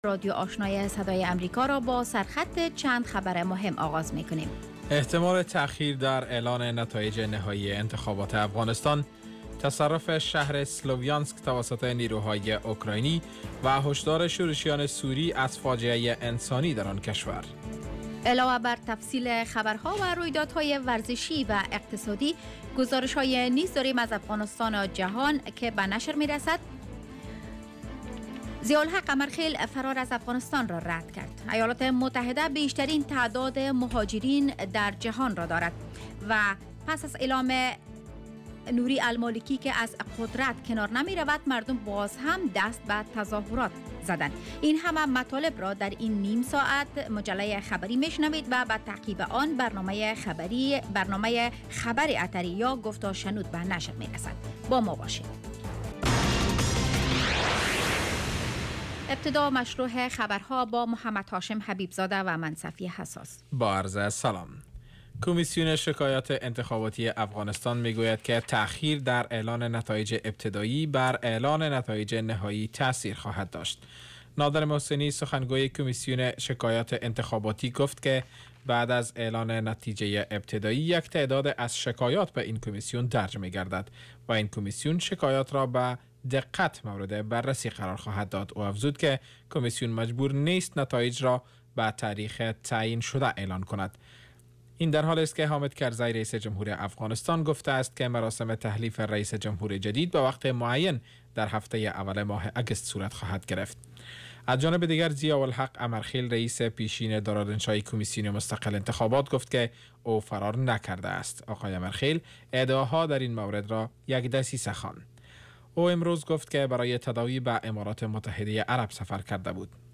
Radio evening first news half-hour show